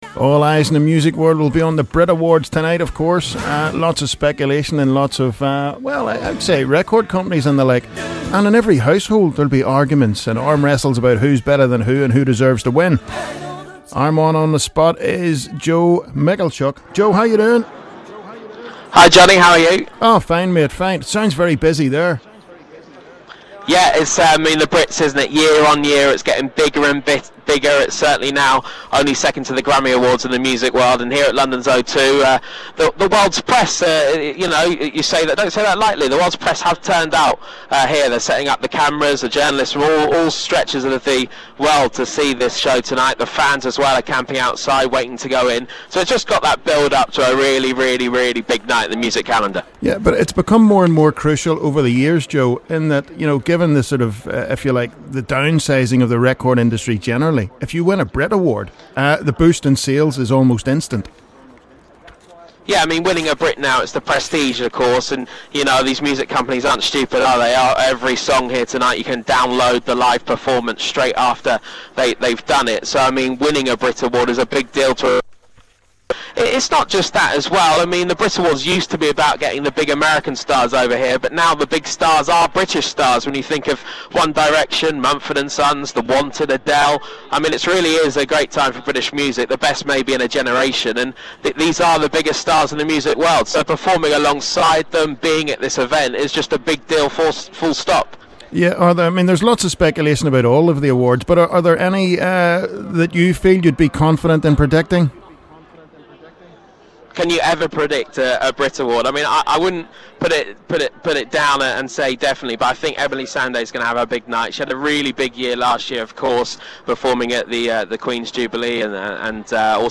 2013 BRIT AWARDS PREVIEW- LIVE FROM THE RED CARPET